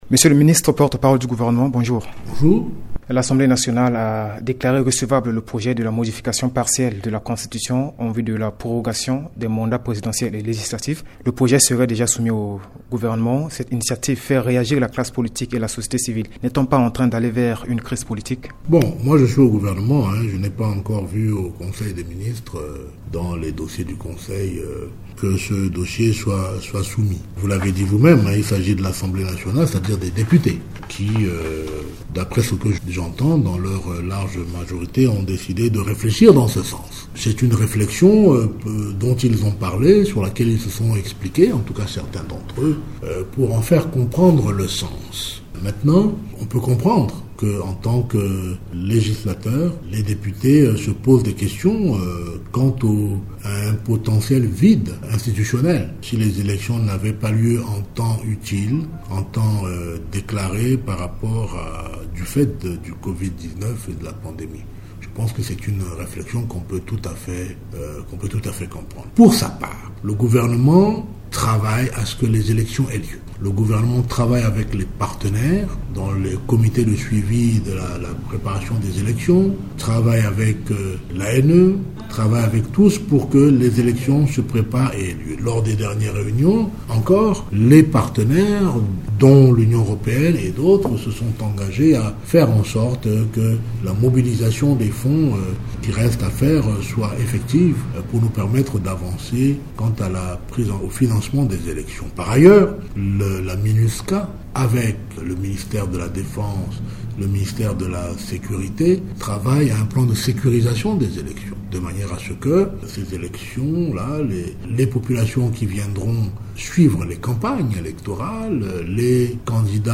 Le ministre de la Communication, porte-parole du Gouvernement, Ange Maxime Kazagui, au cours d’une interview accordée à Radio Ndeke Luka réagit sur l’actualité politique du pays, singulièrement, l’initiative parlementaire visant à modifier partiellement la Constitution pour proroger les mandats présidentiel et législatif en cas de force majeure. Pour lui, en tant que gouvernant, le Gouvernement réfléchit aussi à cette question.